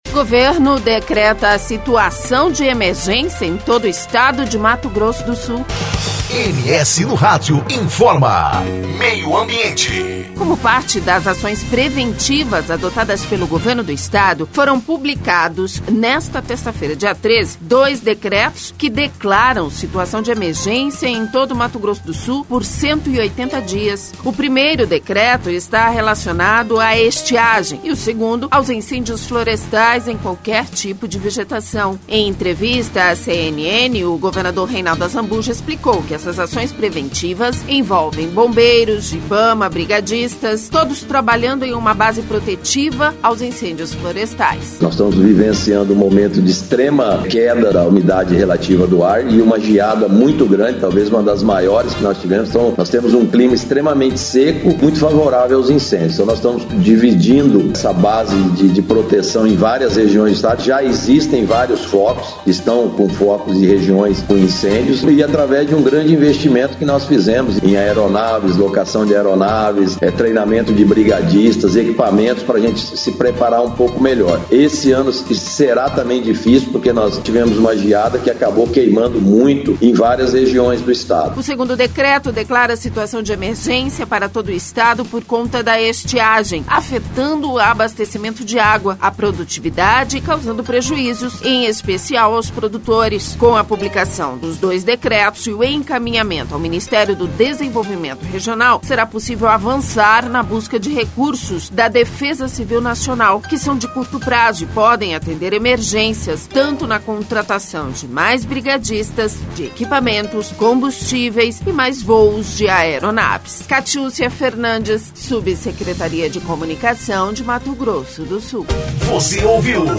Em entrevista à CNN, o governador Reinaldo Azambuja explicou que essas ações preventivas envolvem bombeiros, Ibama, brigadistas, trabalhando em uma base protetiva aos incêndios florestais.